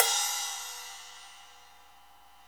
Index of /90_sSampleCDs/Club-50 - Foundations Roland/CYM_xCrash Cyms/CYM_xSplash Cyms